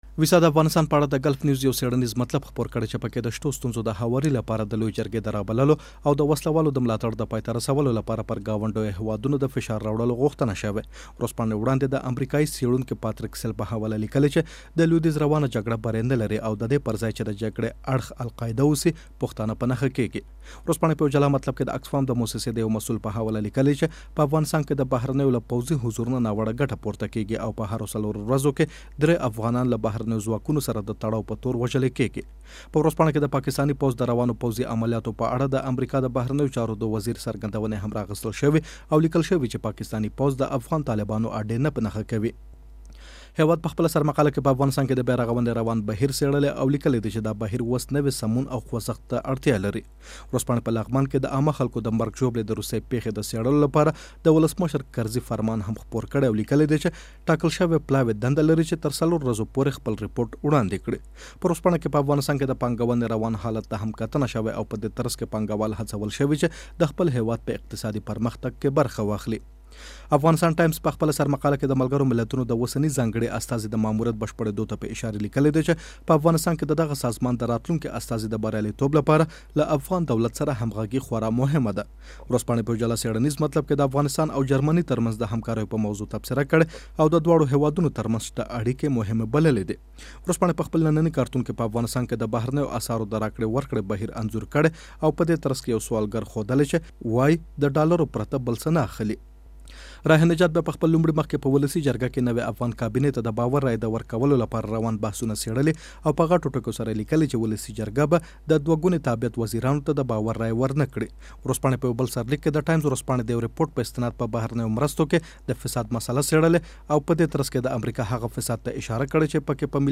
د لویې څارنوالۍ د جنايي جرمونو د تحقیقاتو رييس مرکه